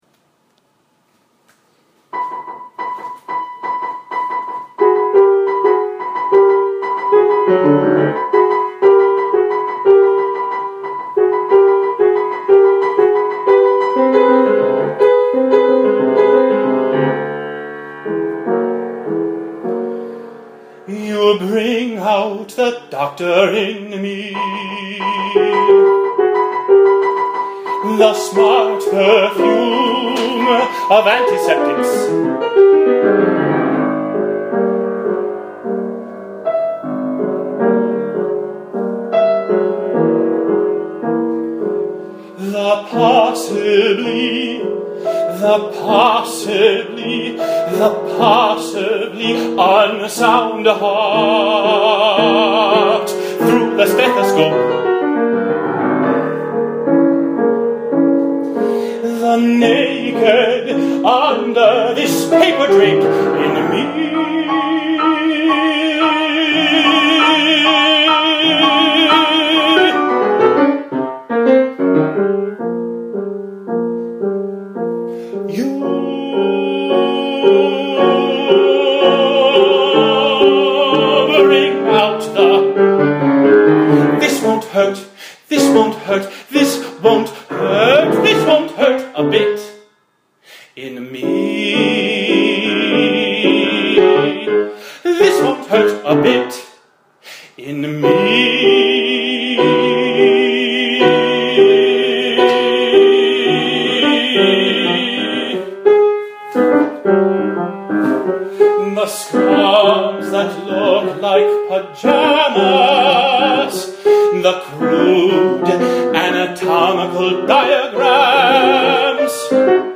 Scored for: tenor or baritone and piano Text
Tenor
pianist
25th anniversary concert